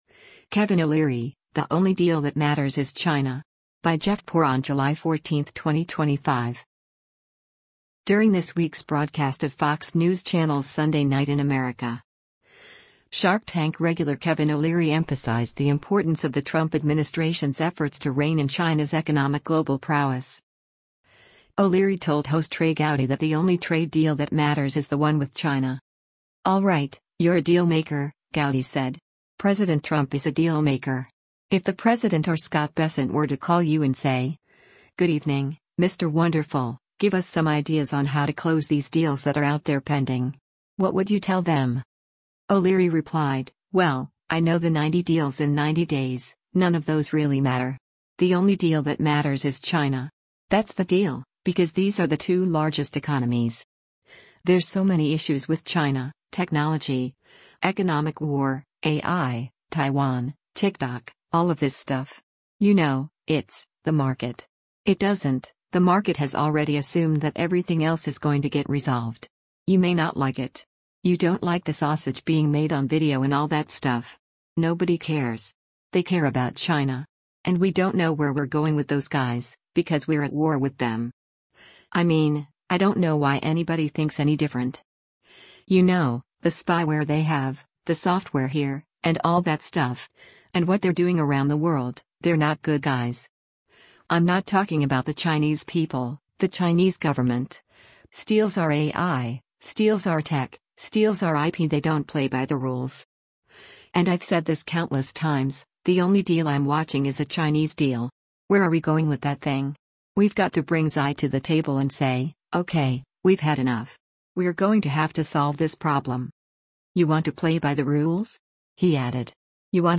During this week’s broadcast of Fox News Channel’s “Sunday Night in America,” “Shark Tank” regular Kevin O’Leary emphasized the importance of the Trump administration’s efforts to rein in China’s economic global prowess.
O’Leary told host Trey Gowdy that the only trade deal that matters is the one with China.